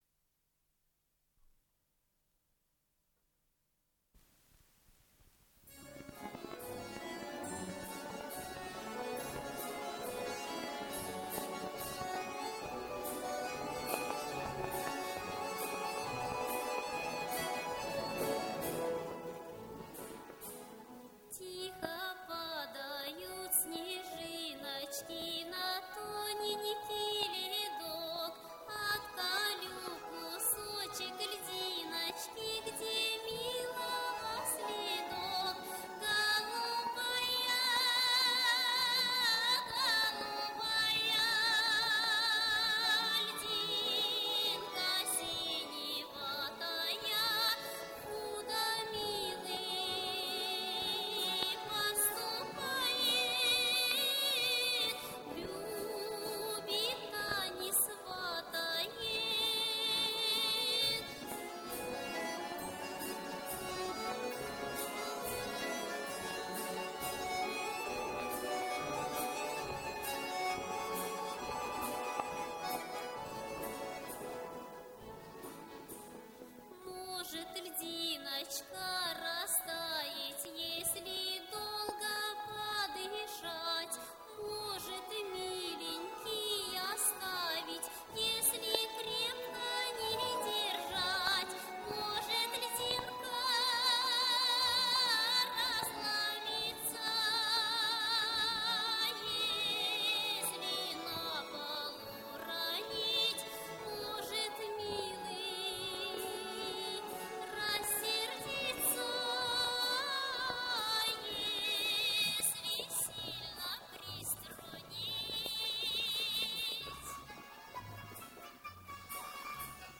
Запись 1982 год Дубль моно.